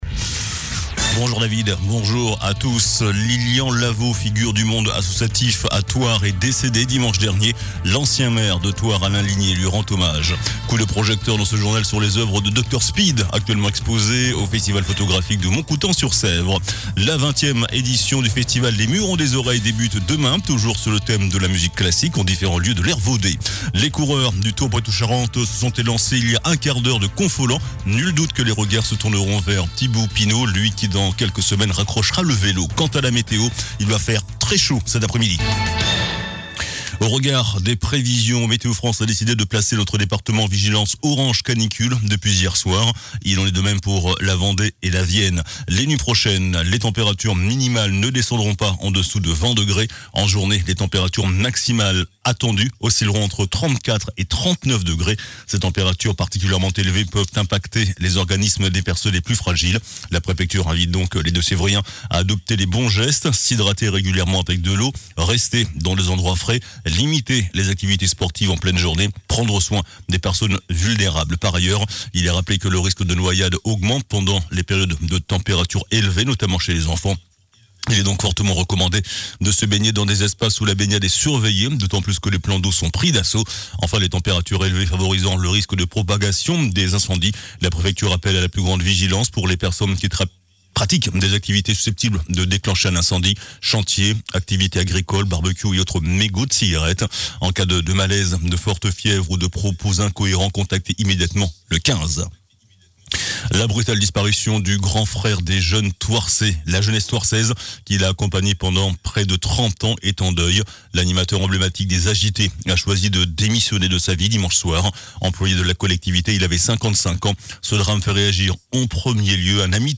JOURNAL DU MARDI 22 AOÛT ( MIDI )